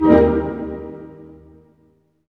Index of /90_sSampleCDs/Roland L-CD702/VOL-1/HIT_Dynamic Orch/HIT_Tutti Hits